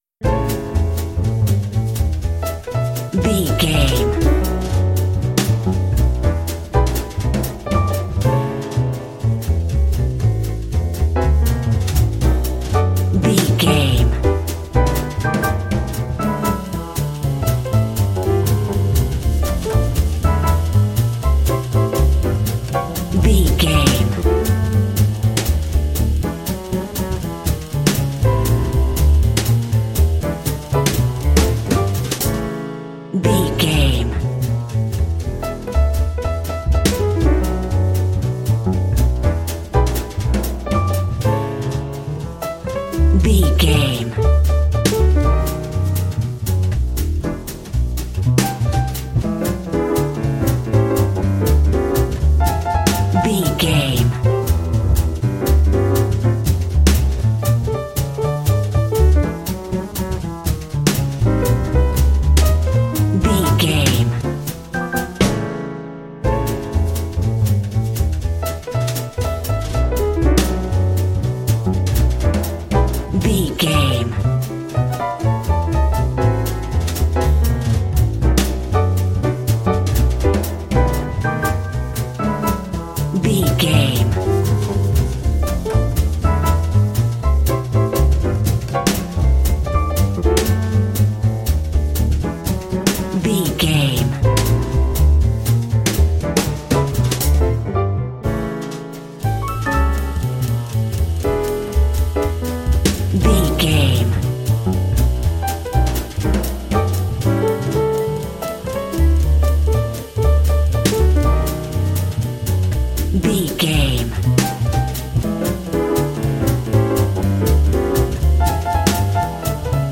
Ionian/Major
Fast
energetic
driving
groovy
piano
drums
double bass
bebop swing
jazz